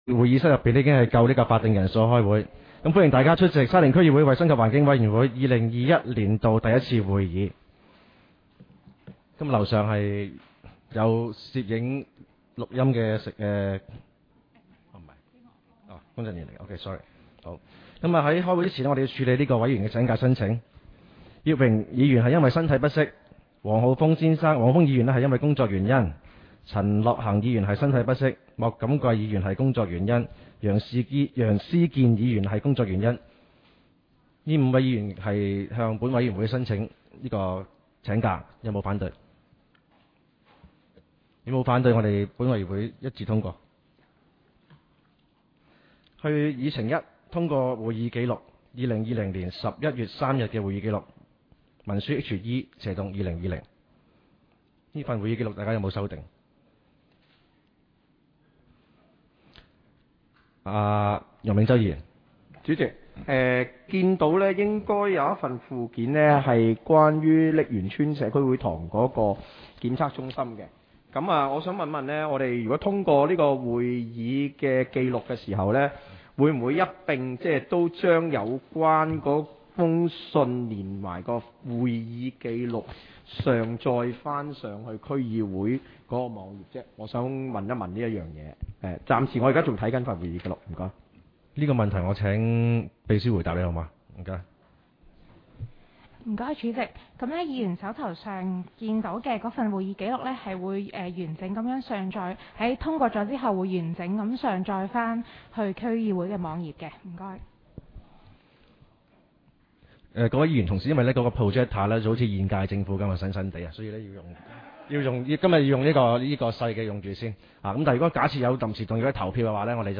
委员会会议的录音记录
地点: 沙田民政事务处 441 会议室